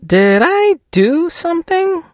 sandy_lead_vo_01.ogg